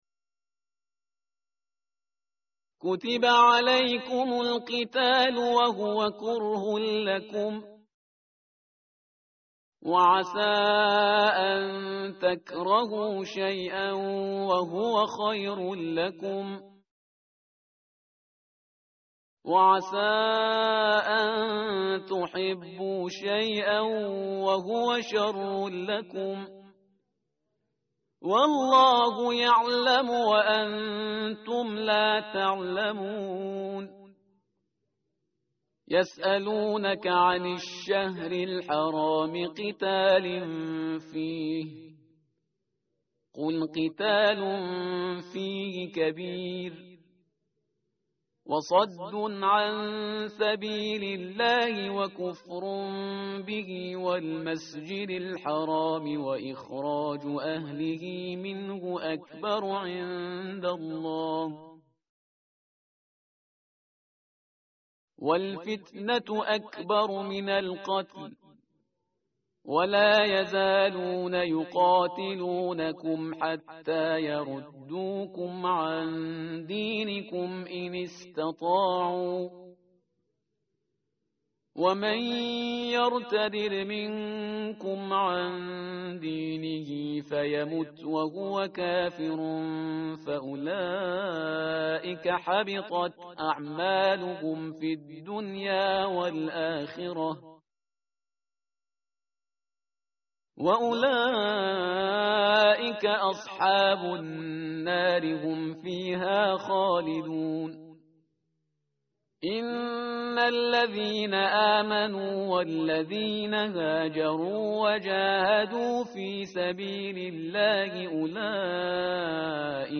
متن قرآن همراه باتلاوت قرآن و ترجمه
tartil_parhizgar_page_034.mp3